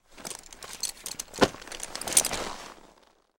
saddle.ogg